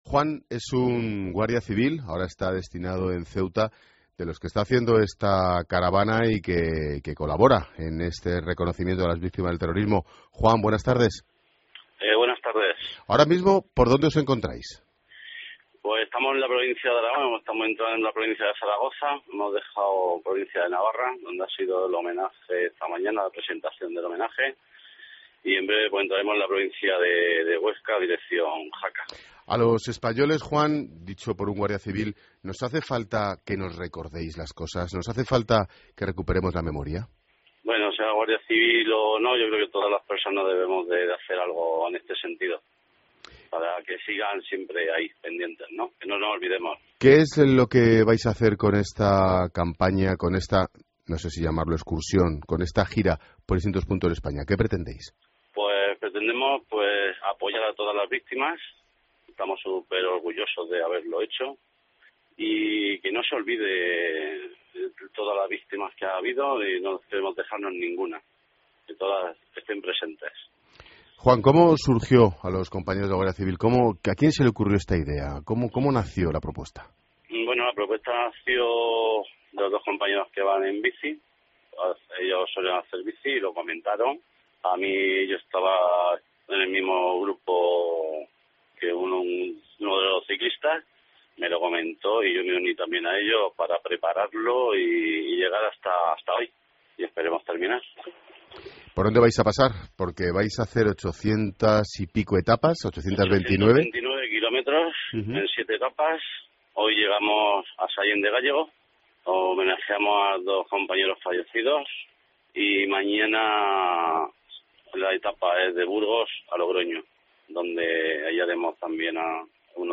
Reportajes COPE
Habla uno de los Guardias Civiles que participan en el homenaje ciclista a las víctimas de ETA.